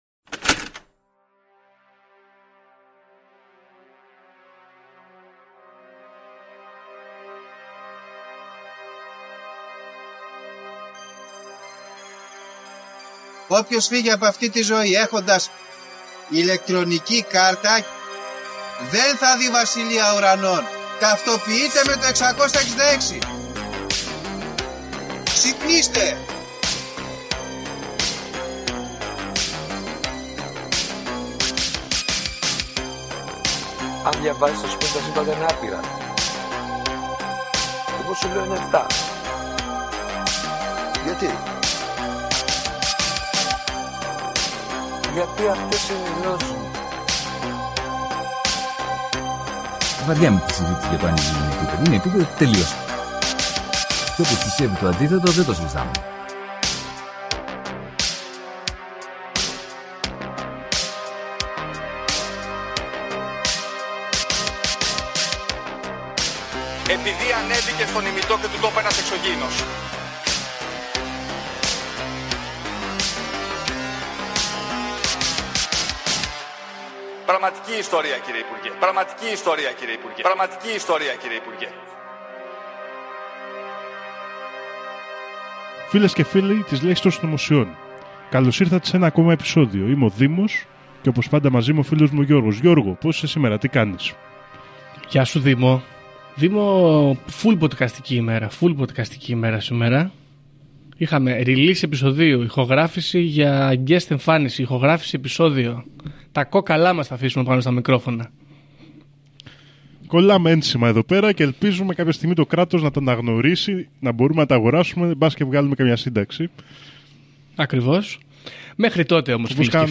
Talking casualy about conspiracies, in Greek.